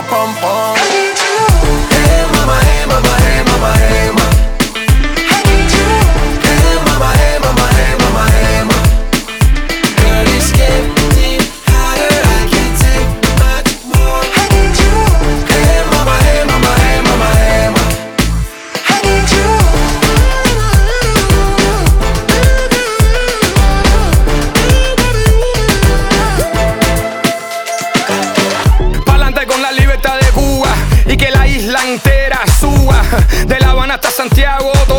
Latin